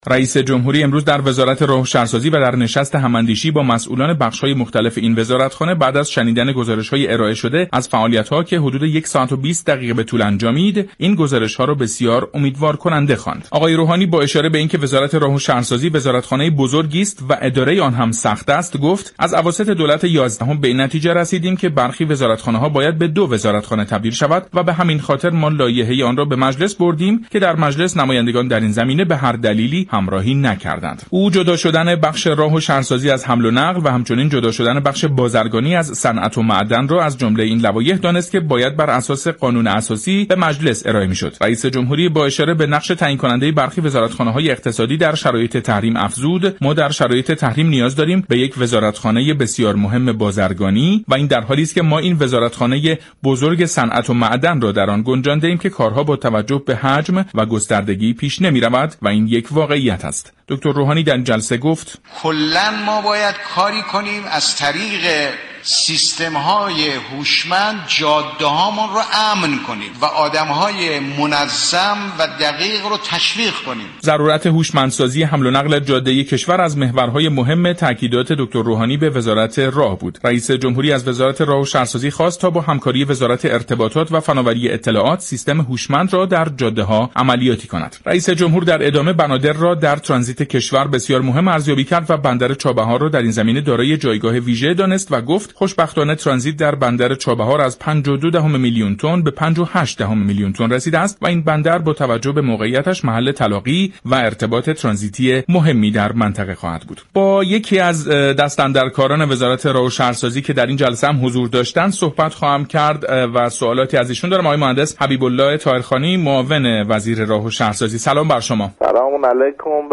مهندس حبیب الله طاهرخانی معاون وزیر راه و شهرسازی در برنامه جهان سیاست رادیو ایران